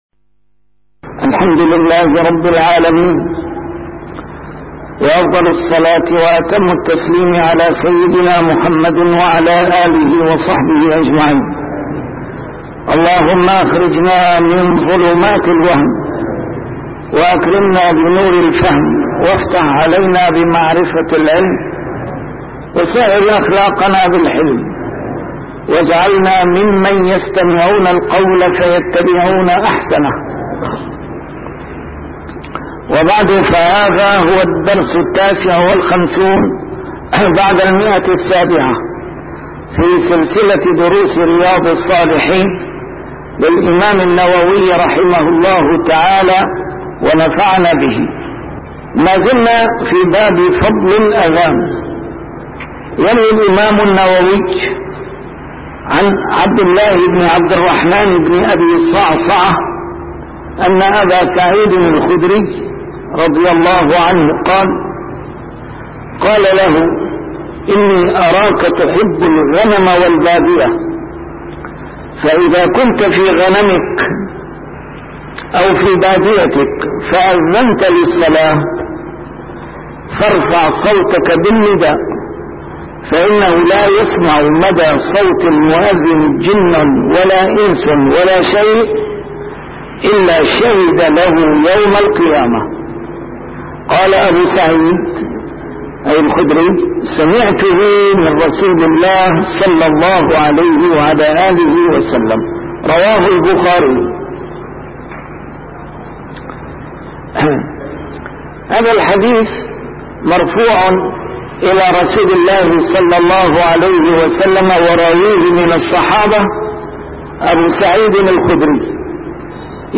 A MARTYR SCHOLAR: IMAM MUHAMMAD SAEED RAMADAN AL-BOUTI - الدروس العلمية - شرح كتاب رياض الصالحين - 759- شرح رياض الصالحين: فضل الأذان